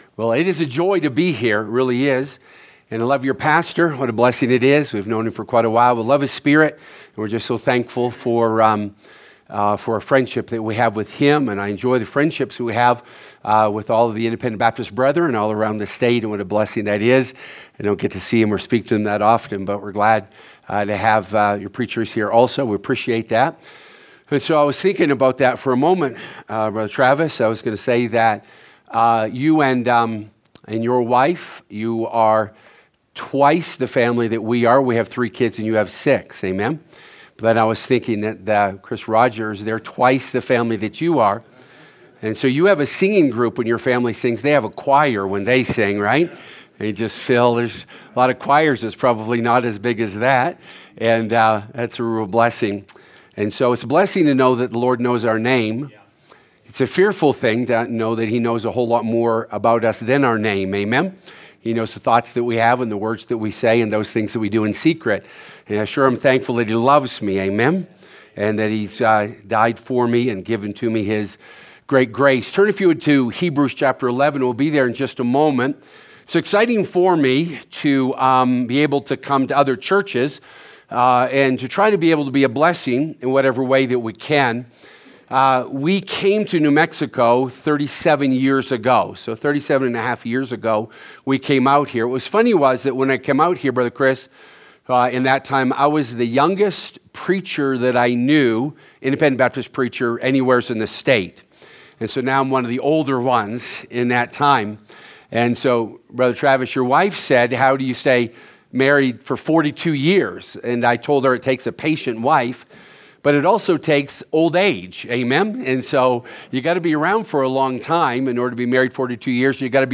Winter Revival 2026 – Moses, Choices in Faith